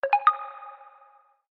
inbound_notification_on_active_room.B-Ih4Jto.mp3